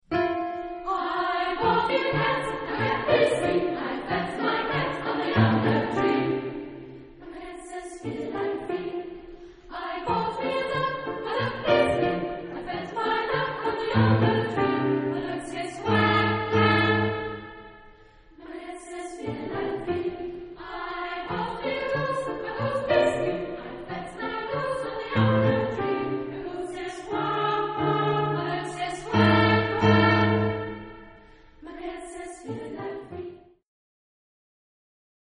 Type de choeur : SSA  (3 voix égales de femmes )
Instruments : Piano (1)
Tonalité : fa majeur